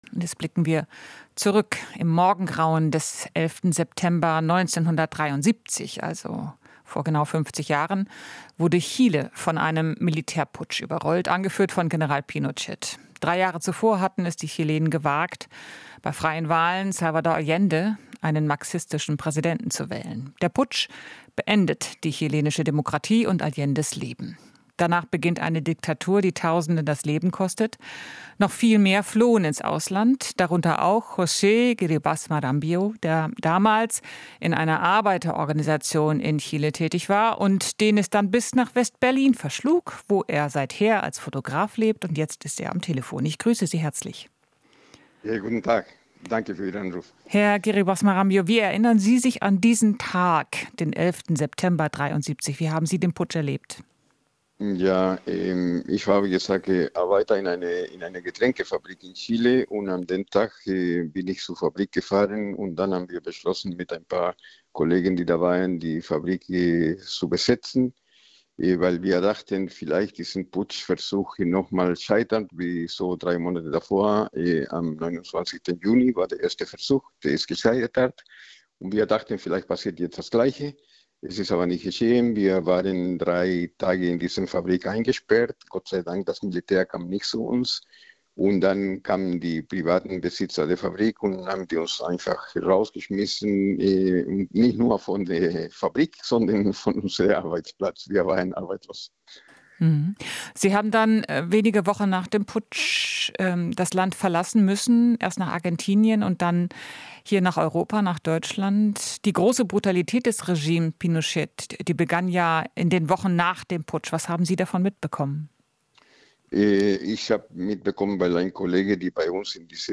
Interview - 50 Jahre Putsch: Augenzeuge erinnert sich an Brutalität in Chile